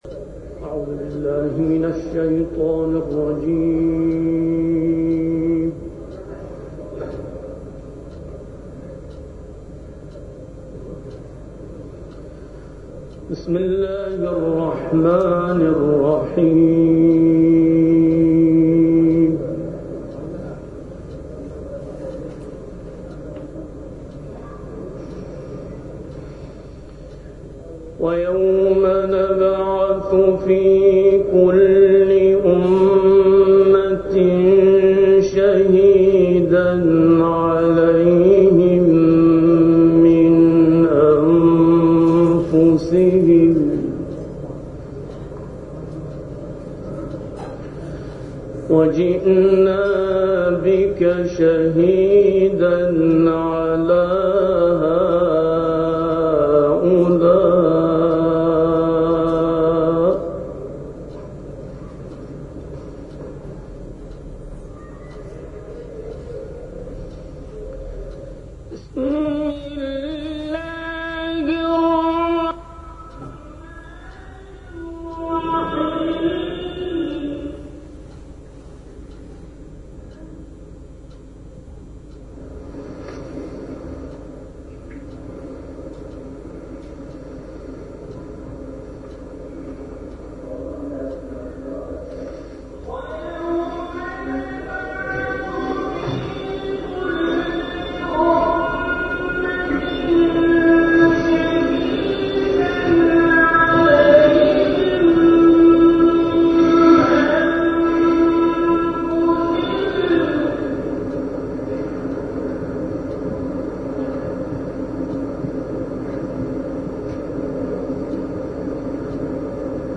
هنگام تلاوت این قاری بین المللی، صوت تلاوت از شبکه های تلویزیونی با نقص فنی مواجه گردید.